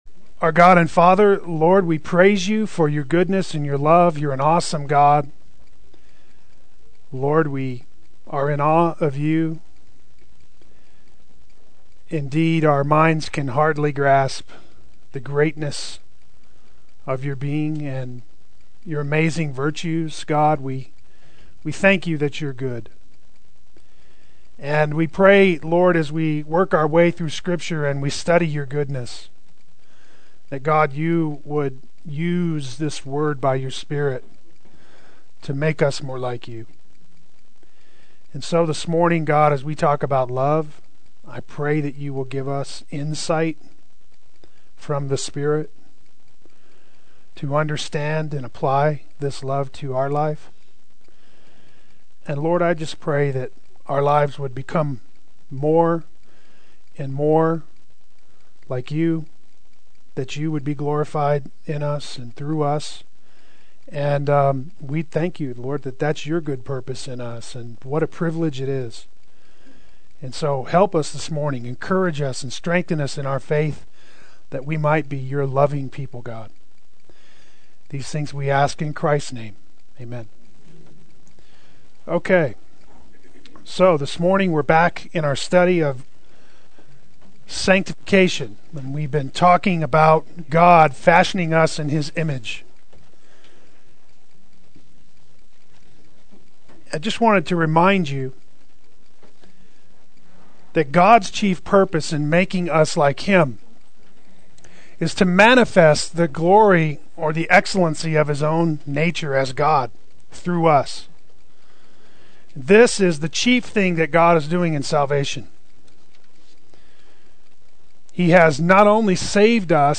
The Crowning Virtue Adult Sunday School